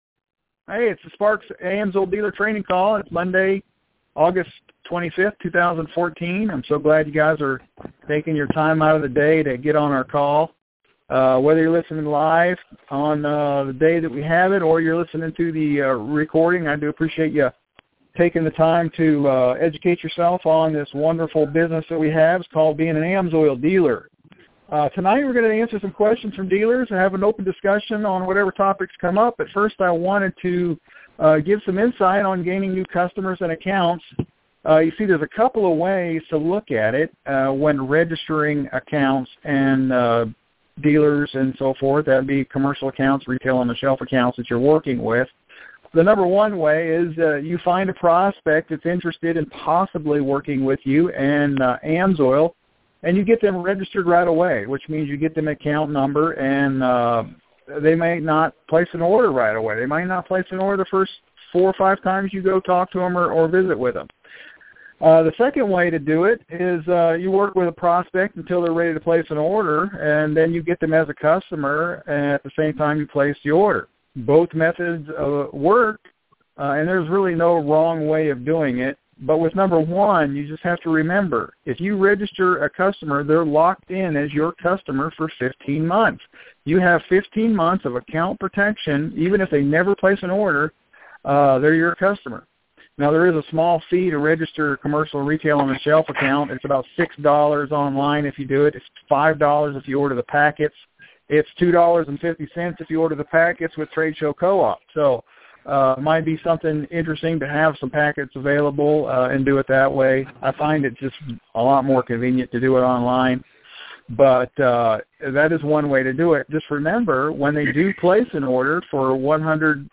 AMSOIL Dealer training call. Listen to this weeks call where we have a Q&A with several AMSOIL Dealers.